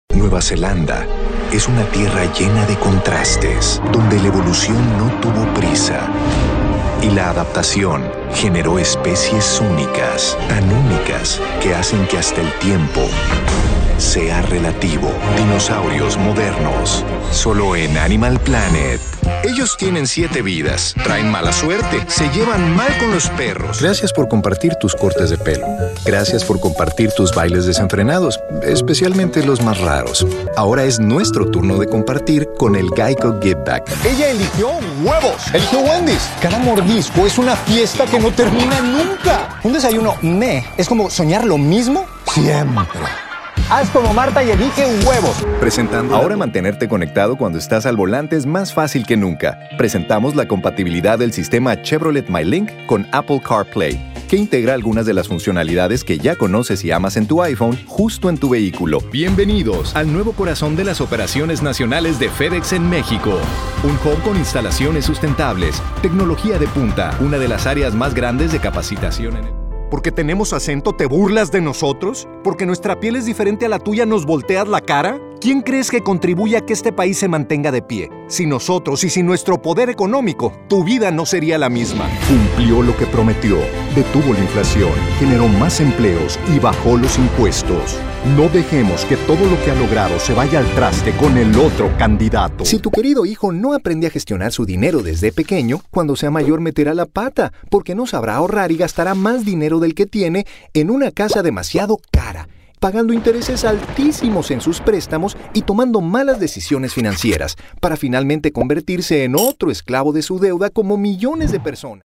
Warm, friendly Spanish speaking talent with a fantastic range